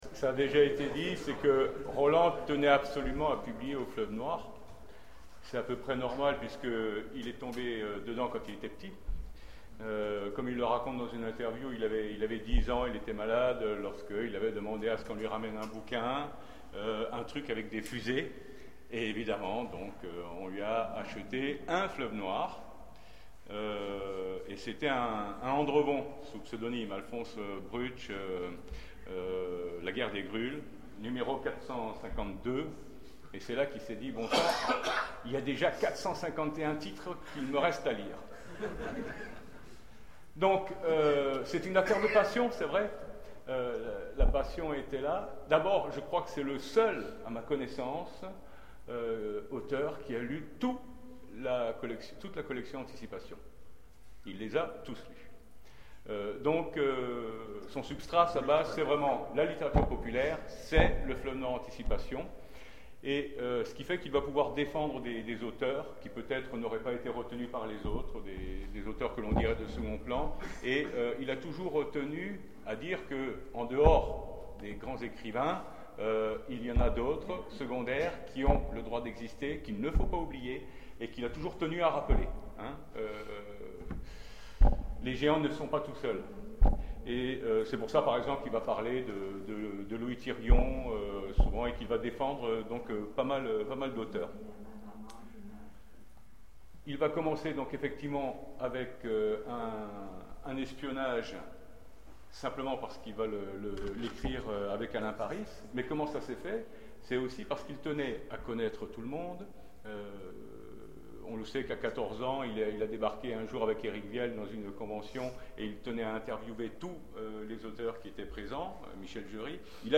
- le 31/10/2017 Partager Commenter Hommage à Roland C.Wagner : Roland et le Fleuve Noir Télécharger le MP3 à lire aussi Roland C. Wagner Genres / Mots-clés Roland C.Wagner Conférence Partager cet article